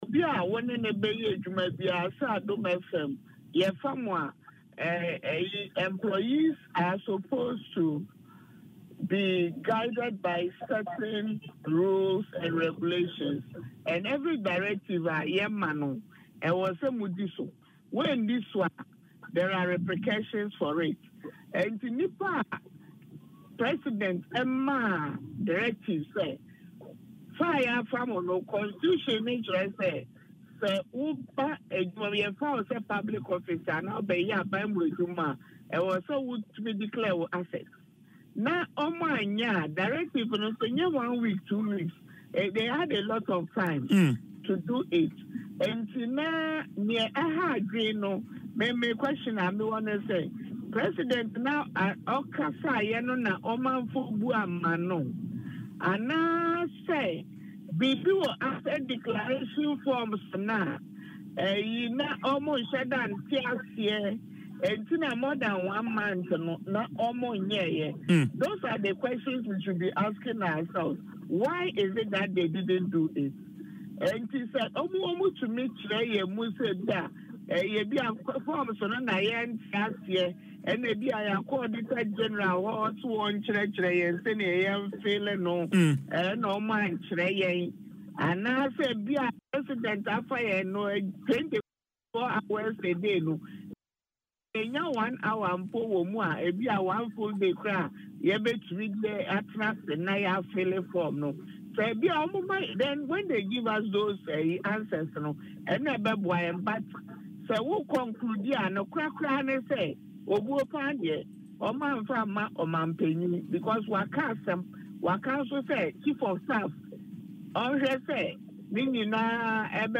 In an interview on Adom FM’s Dwaso Nsem, she expressed concern that the defaulters disregarded the directive, emphasizing that employees are expected to follow rules and regulations, and failure to do so should result in consequences.